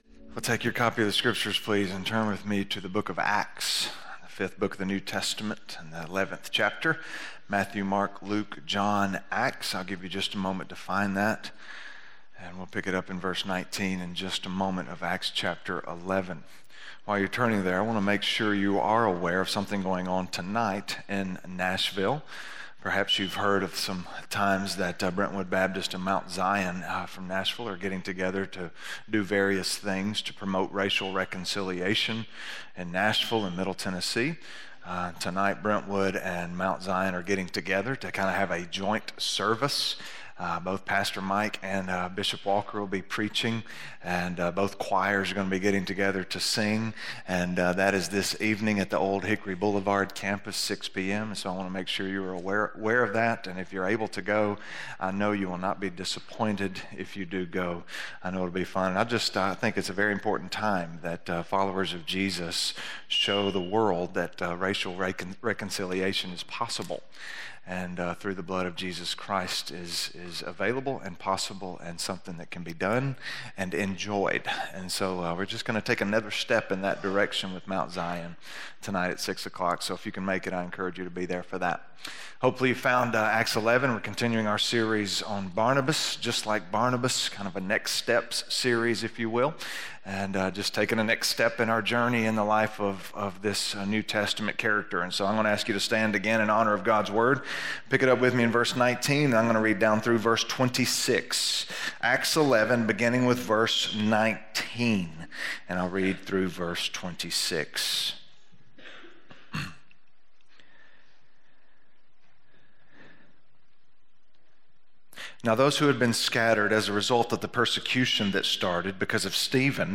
Just Like Barnabas: Leading - Sermon - West Franklin